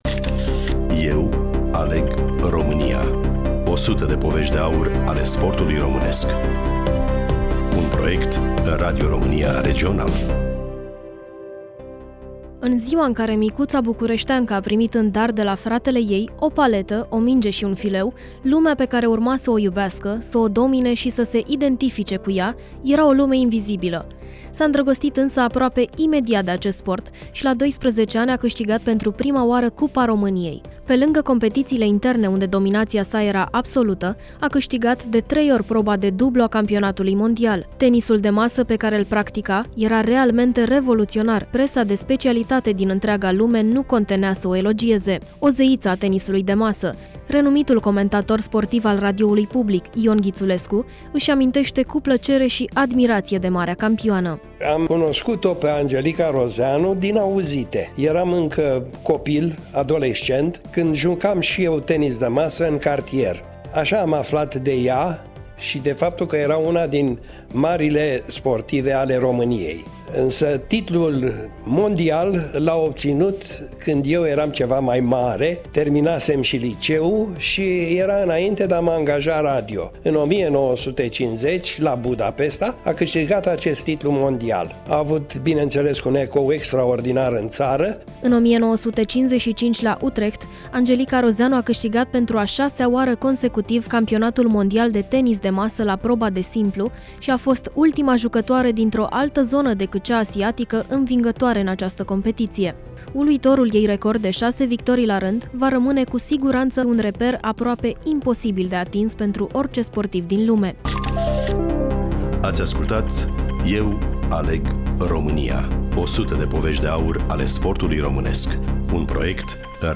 Studioul Bucuresti FM